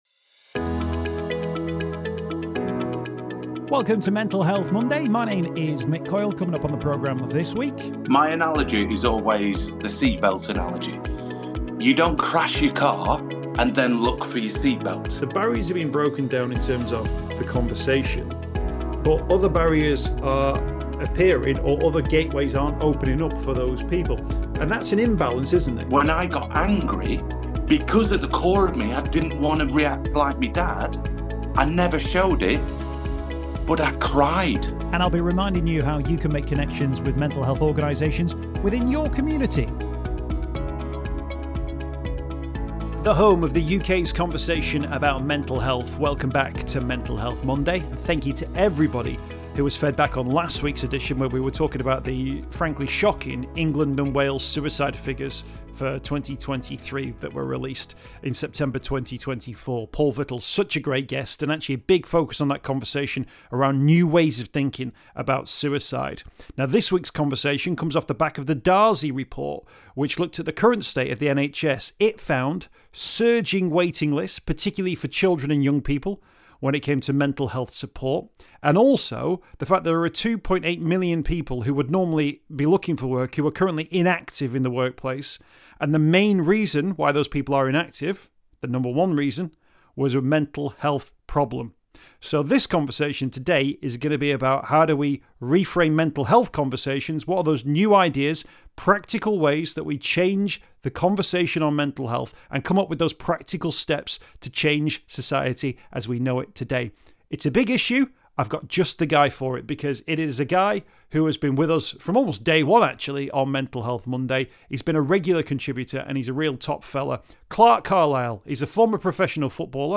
Find out more about It's Mental Wellbeing Collective Note: This episode features some sound issues for reasons explained at the beginning of the podcast.